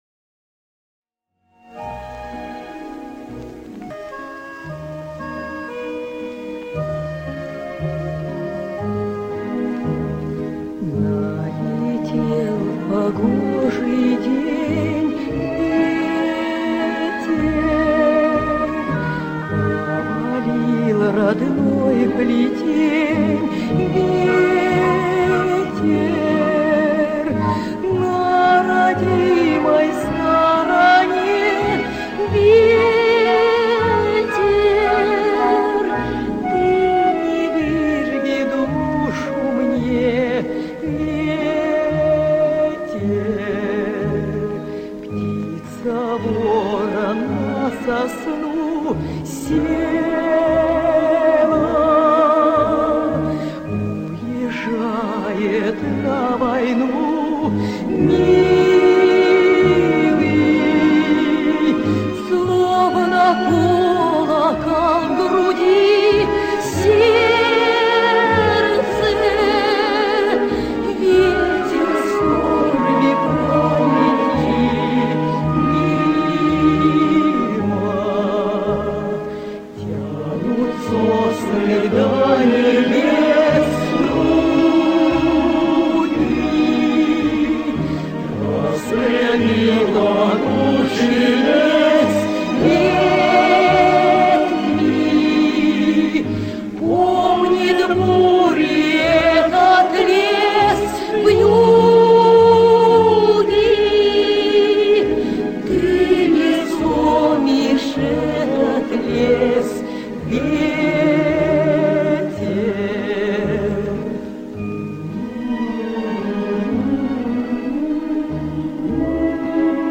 Выдерну из  фильма,  хоть такой  файл будет.